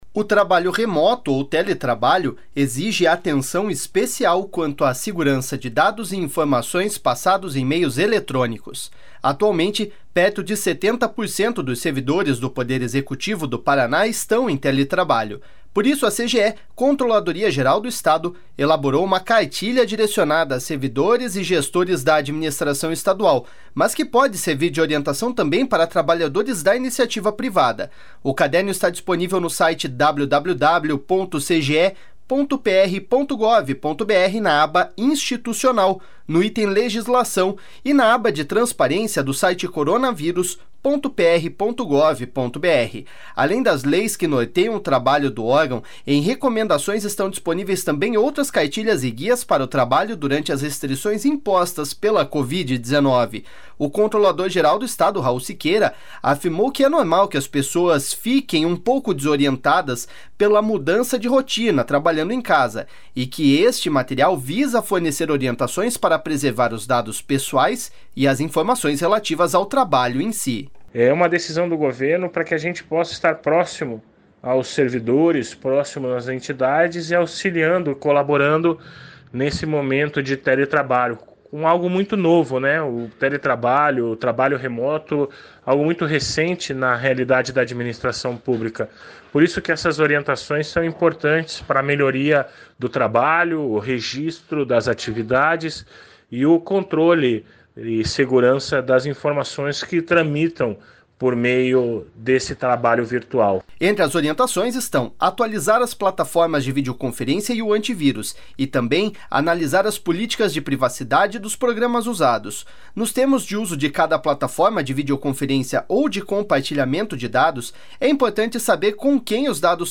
O controlador-geral do Estado, Raul Siqueira, afirmou que é normal as pessoas ficarem um pouco desorientadas pela mudança de rotina, trabalhando em casa, e que este material visa fornecer orientações para preservar os dados pessoais e as informações relativas ao trabalho em si.// SONORA RAUL SIQUEIRA.//